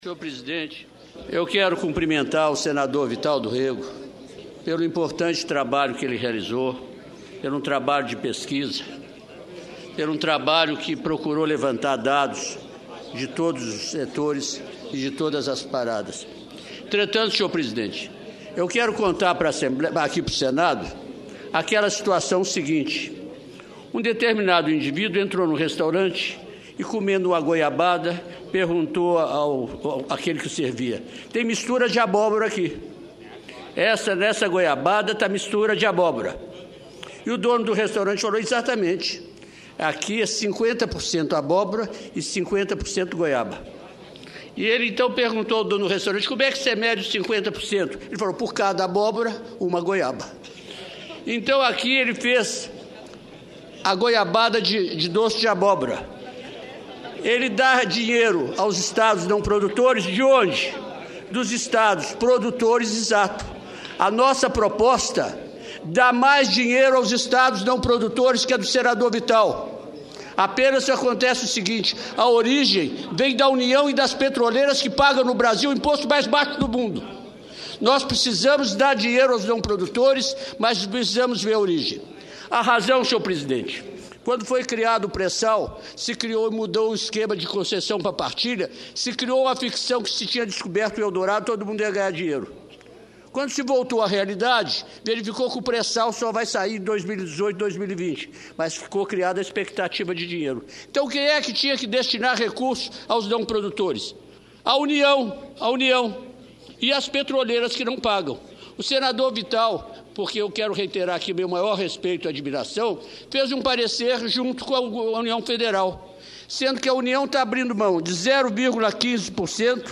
Pronunciamento do senador Francisco Dornelles
Tópicos: Pronunciamento